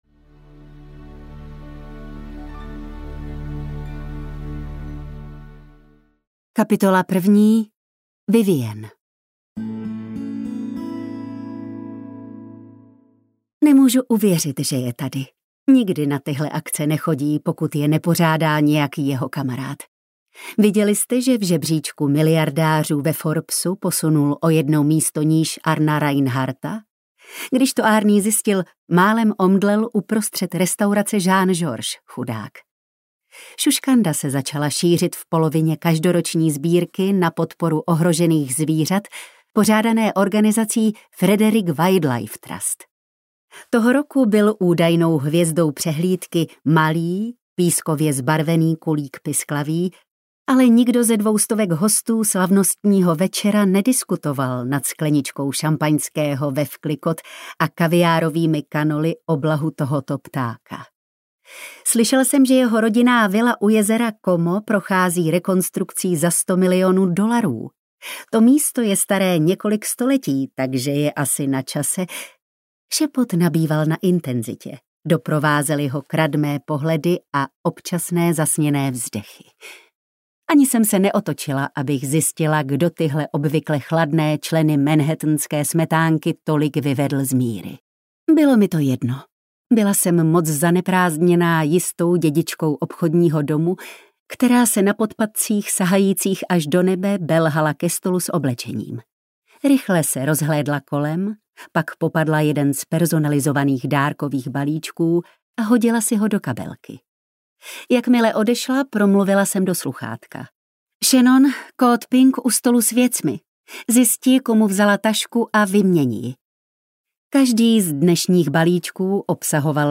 Král hněvu audiokniha
Ukázka z knihy